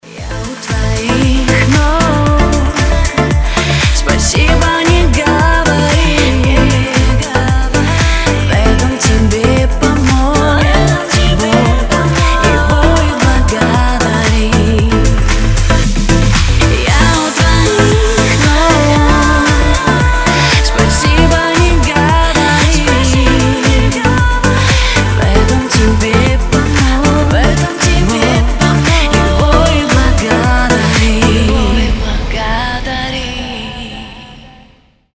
поп
Club House
remastered